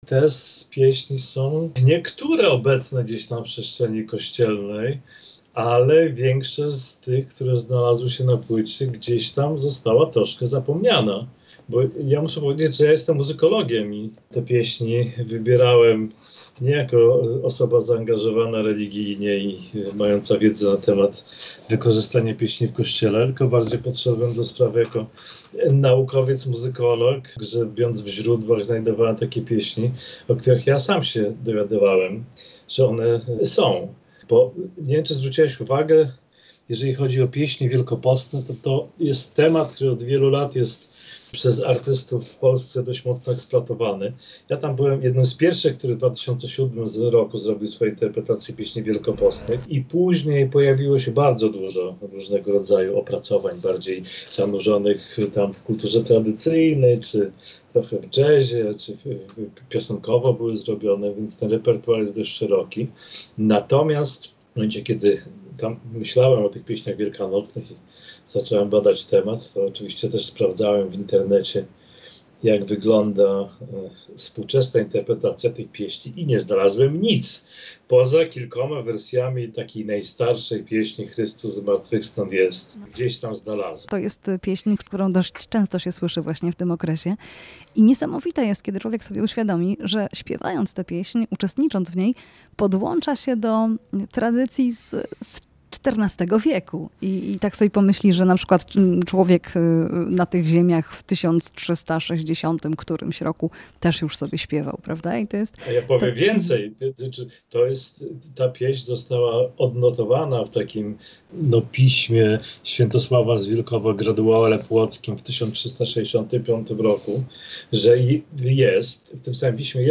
POLSKA PŁYTA TYGODNIA EXTRA - "Zorza prześliczna wynika" [POSŁUCHAJ ROZMOWY]